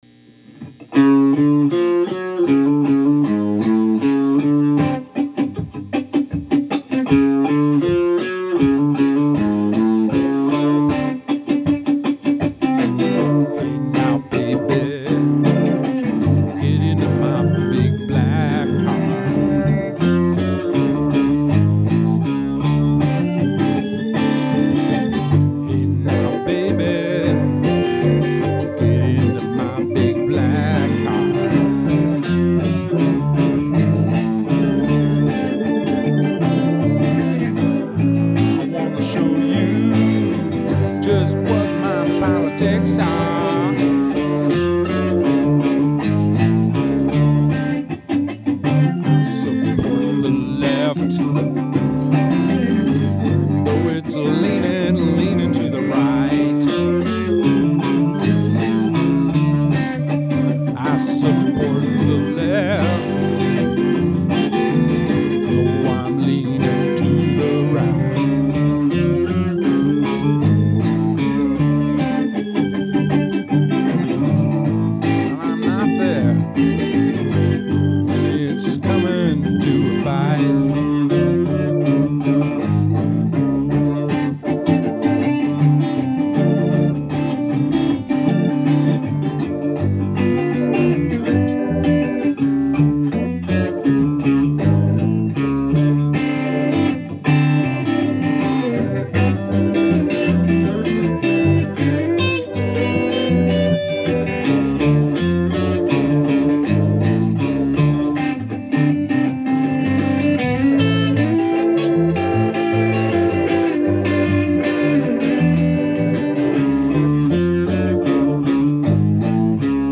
Hammond Aurora
bass
guitar